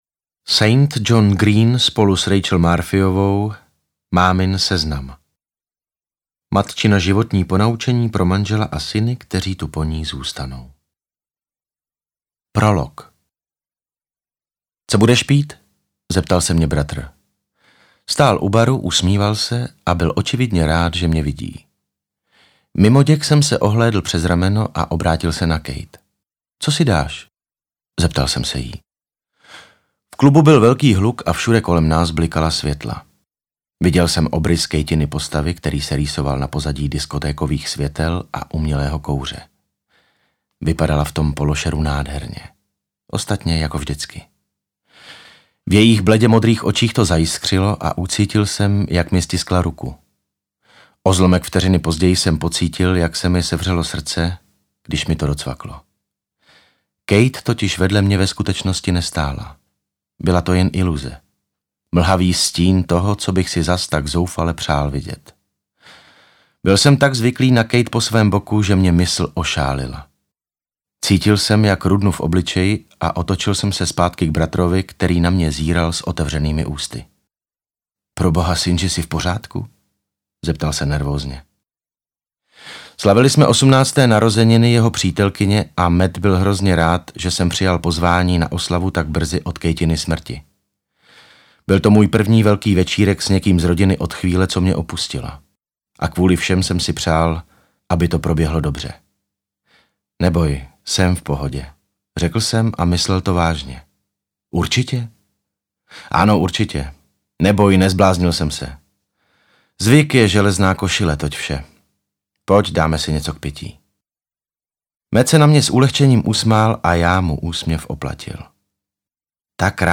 Interpret:  David Novotný
AudioKniha ke stažení, 13 x mp3, délka 11 hod. 3 min., velikost 605,3 MB, česky